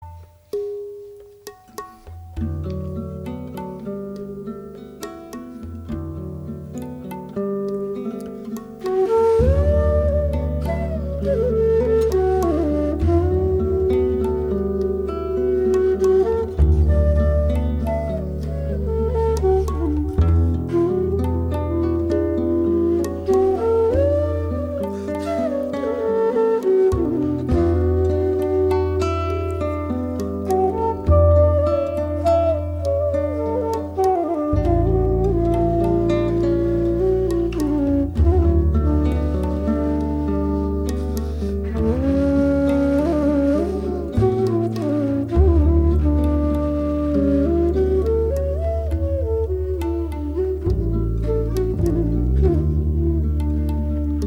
Genre: World Fusion.
bansuri
guitar
drums & percussion
tabla
double bass & electric bass
Recorded at Livingstone Studios, London